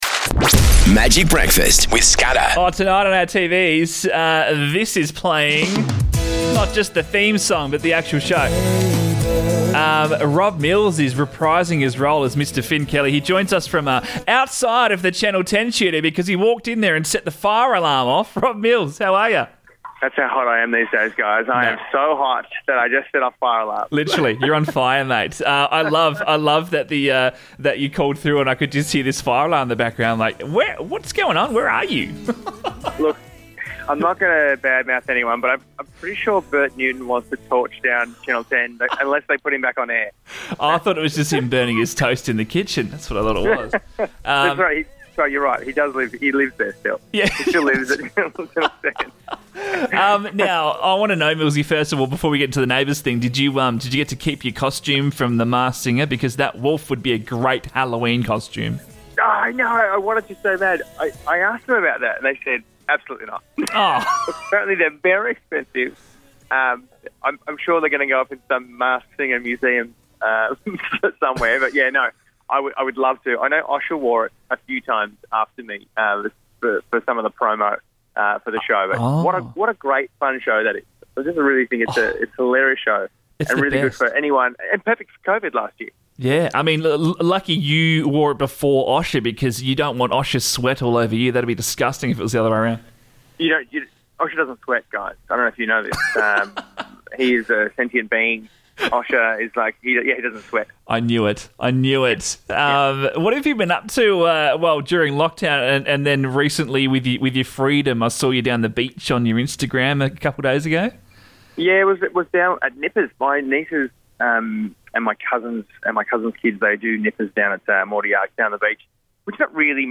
from outside the Channel 10 building